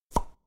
toy-take.mp3